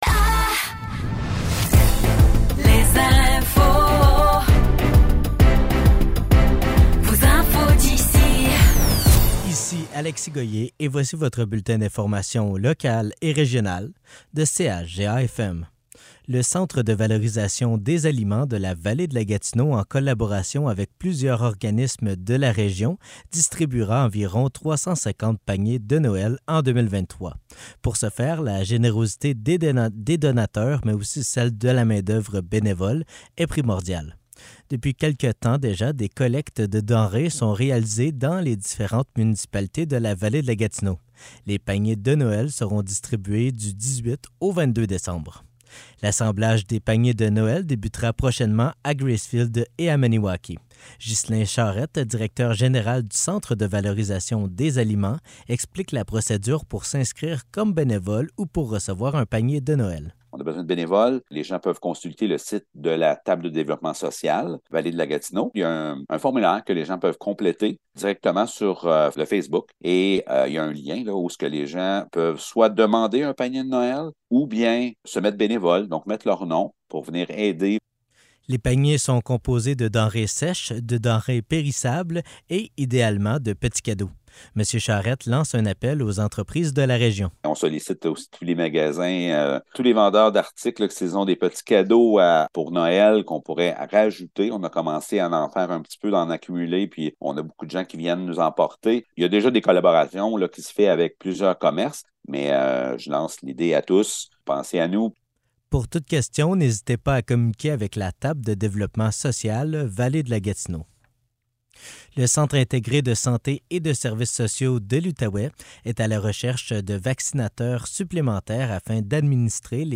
Nouvelles locales - 29 novembre 2023 - 15 h